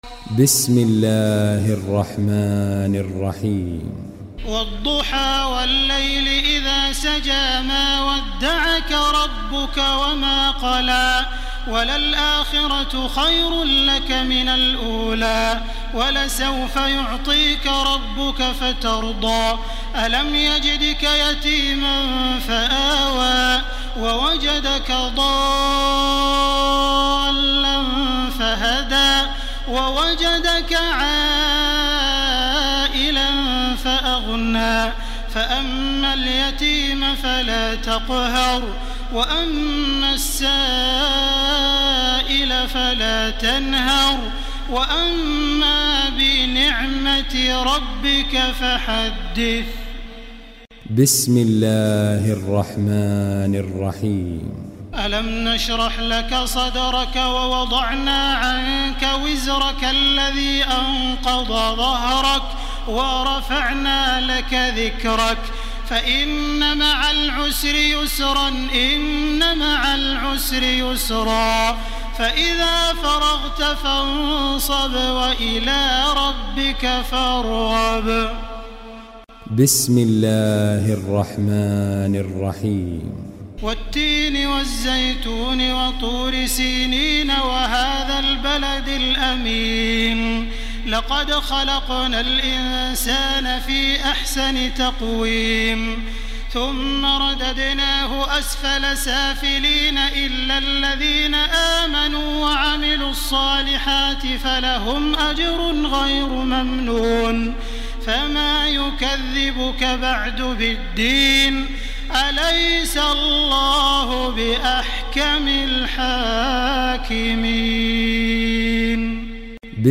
تراويح ليلة 29 رمضان 1430هـ من سورة الضحى الى الناس Taraweeh 29 st night Ramadan 1430H from Surah Ad-Dhuhaa to An-Naas > تراويح الحرم المكي عام 1430 🕋 > التراويح - تلاوات الحرمين